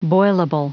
Prononciation du mot boilable en anglais (fichier audio)
Prononciation du mot : boilable